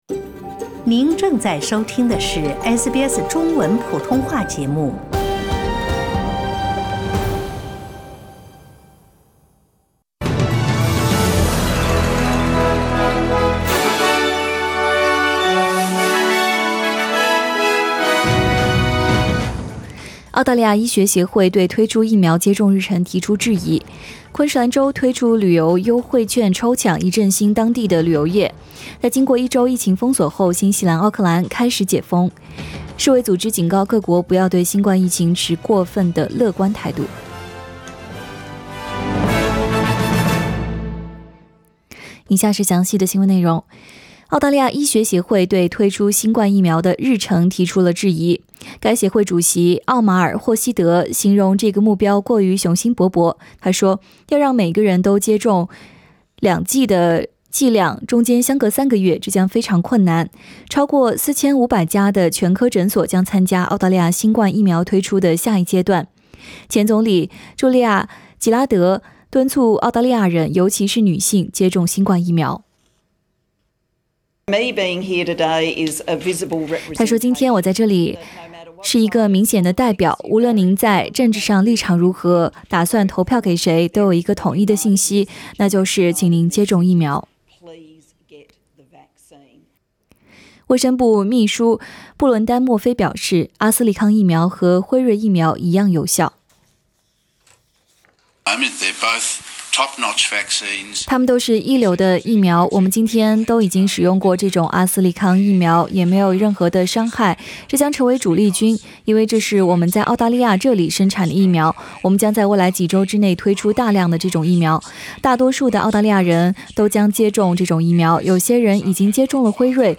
SBS Mandarin morning news Source: Getty Images